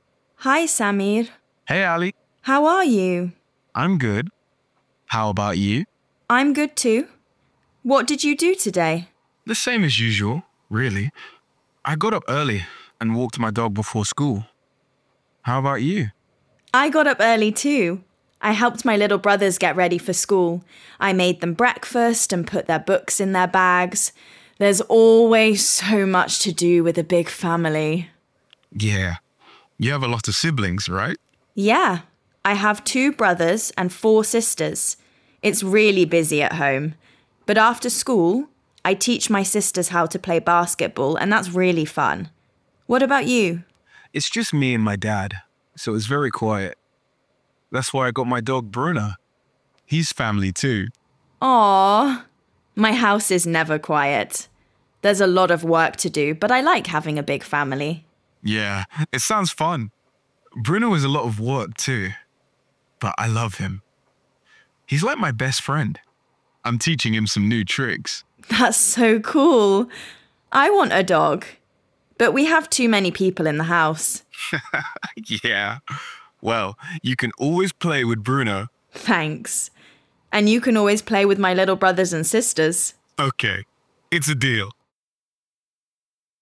• Slide 4. Tell the learners that they're going to listen to two teenagers, Ali and Samir, talking about their families.
A1_Dialogue_Family_v1.mp3